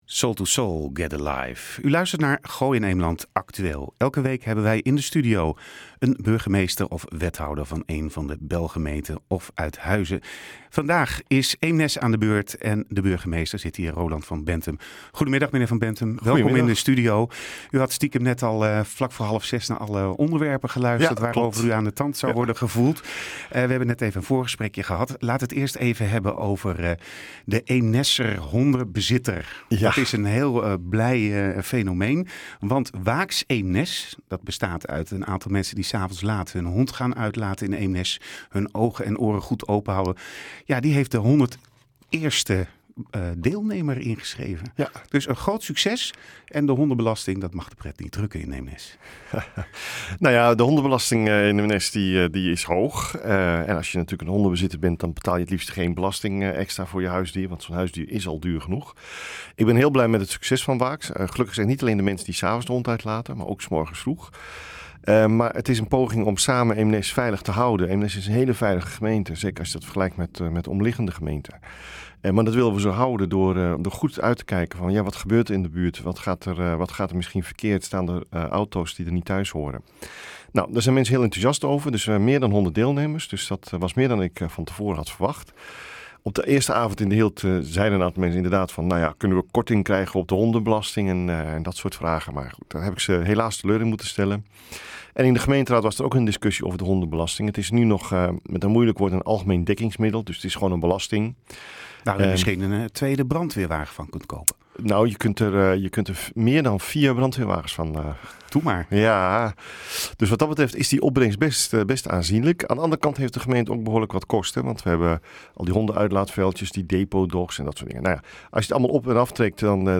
In de rubriek De Burgemeester van ... geeft Roland van Benthem toelichting op de achtergronden bij het nieuws in zijn gemeente.